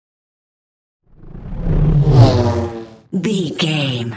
Scifi pass by vehicle
Sound Effects
futuristic
pass by
vehicle